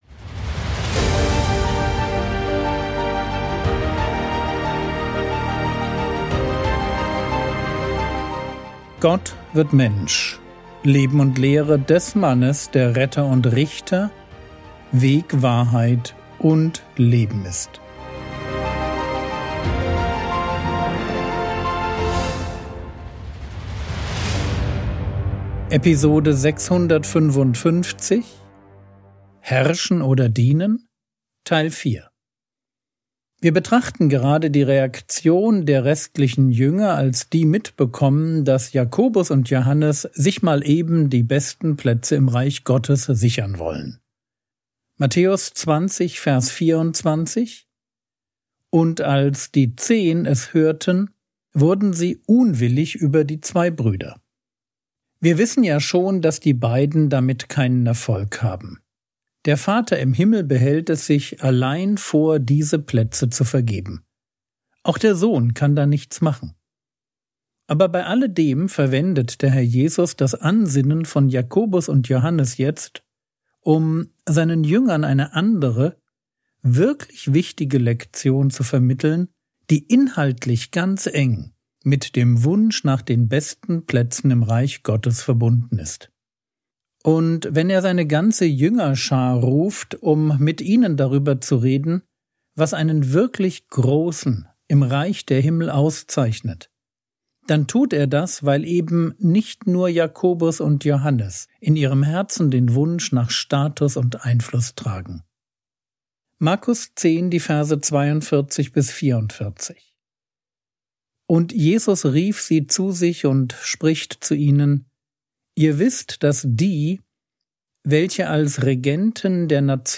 Episode 655 | Jesu Leben und Lehre ~ Frogwords Mini-Predigt Podcast